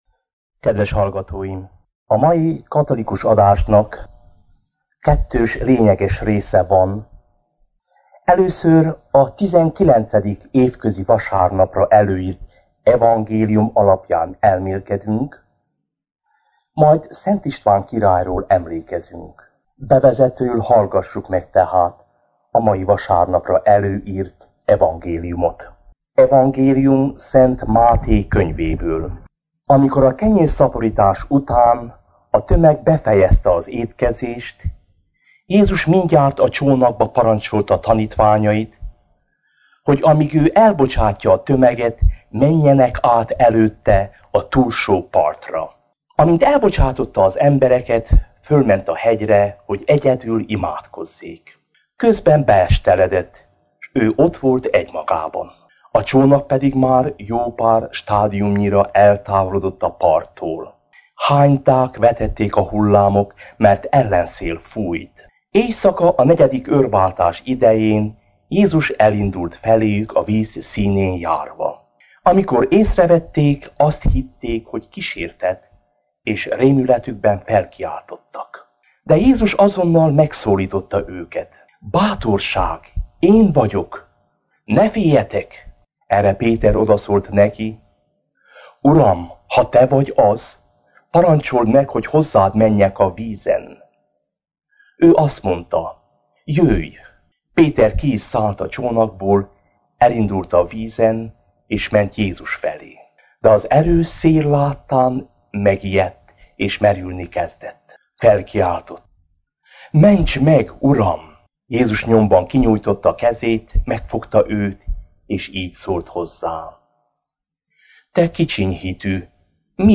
A mai rádióadásnak két fő része van: – elmélkedés a vasárnapi evangélium alapján – és Szent István királyról való megemlékezés.